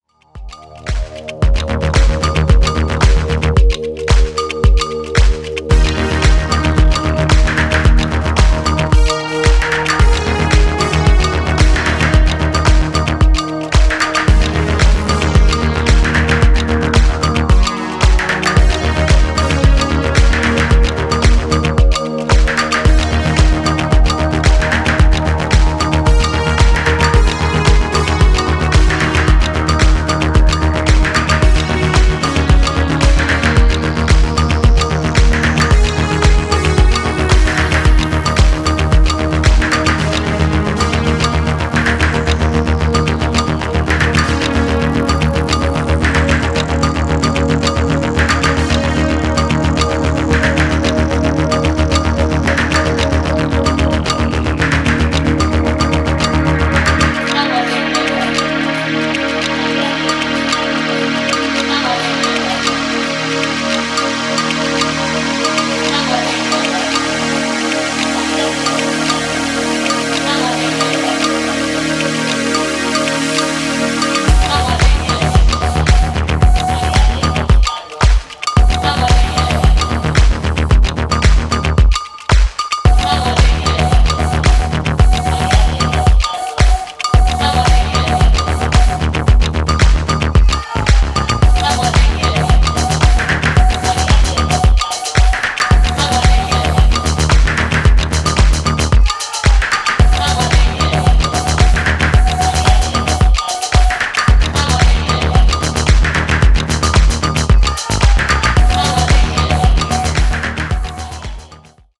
trippy and definitely made for the dancefloor.
House